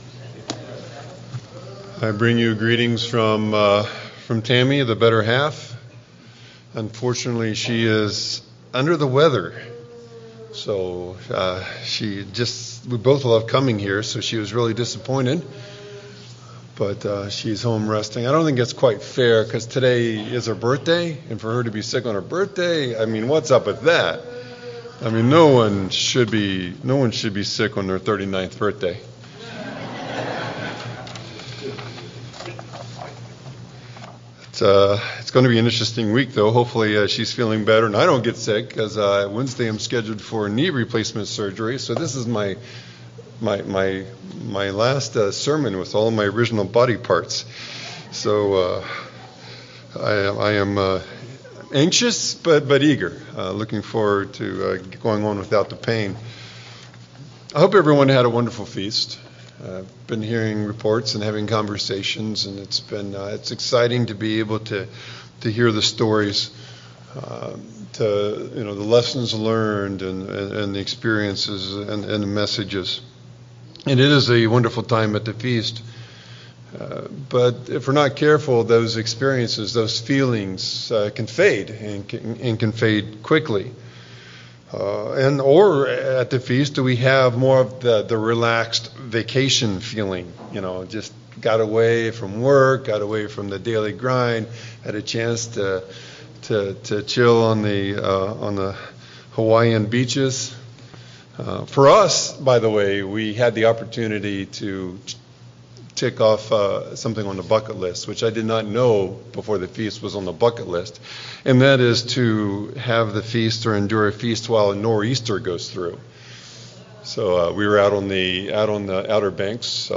In this heartfelt message, we’re reminded that God Himself is a master builder—and He has called His people to share in that work. Using scriptures from Job, John, Nehemiah, and the apostolic writings, the sermon explores the difference between being a builder and being merely a tenant in God’s household.